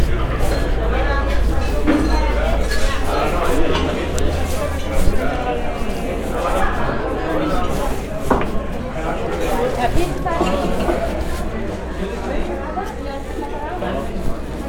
chatter-5.ogg